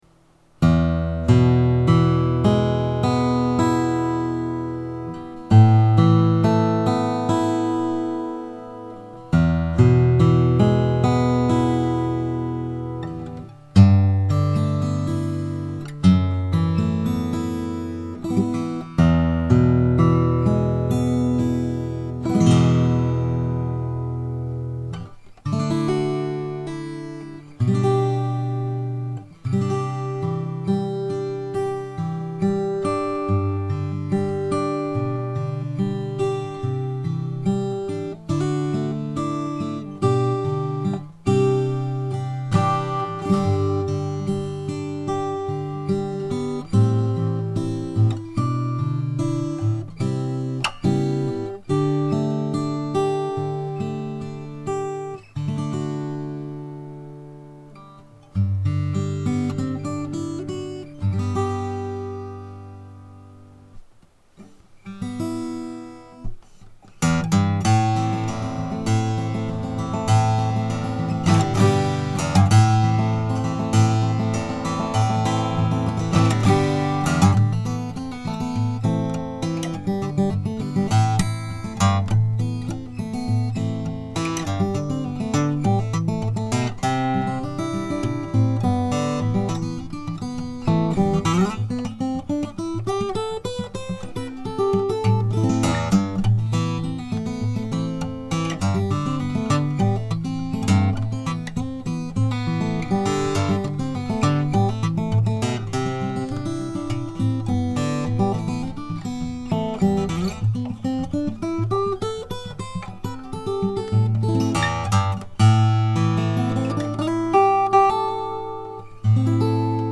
ビンテージ ヤマハ ＦＧ-150 赤ラベル サウンド w(°ｏ°)w おおっ！！
自然なリバーブ感！高音の切れ味。 ビンテージの音ですね。 サドルの底面のフィットにより弦の輪郭がはっきりと出るようになりました。